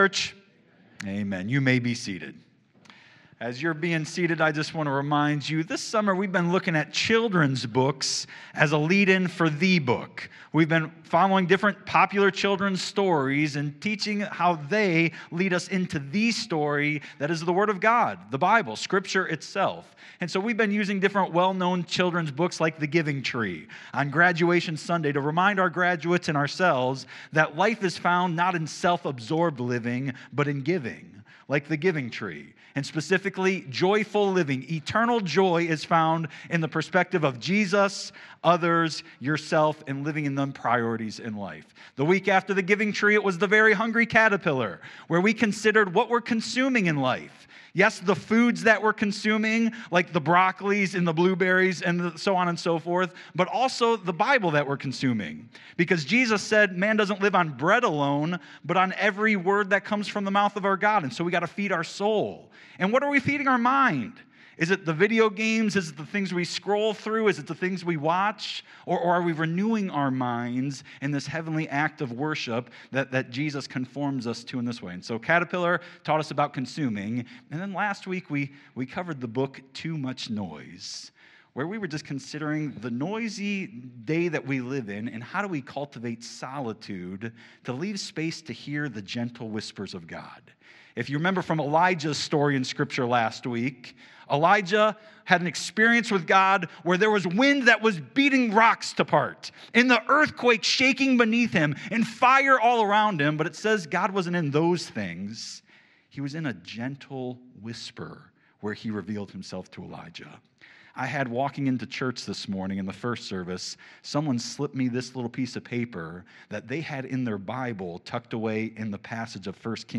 Sermons | First Church Bellevue
Guest Speaker